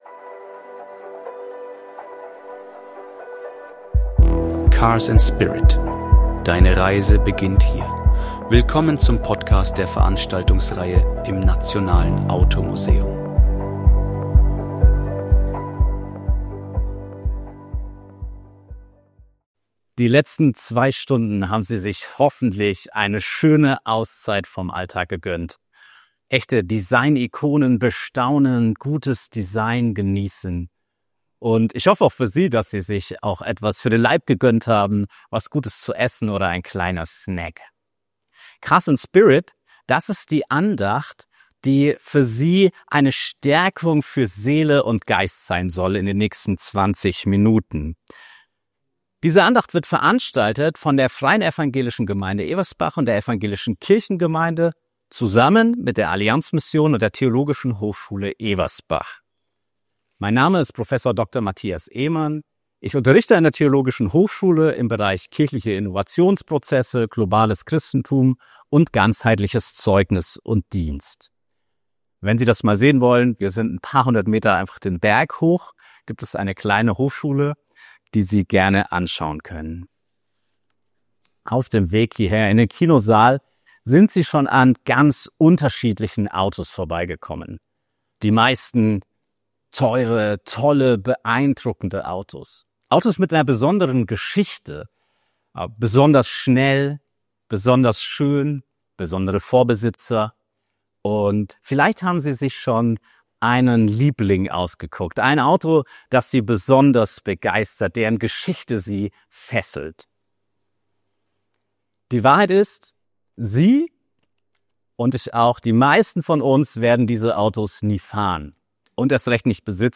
Willkommen zu einer besonderen Andacht im Nationalen Automuseum – dort, wo Designikonen auf spirituelle Impulse treffen.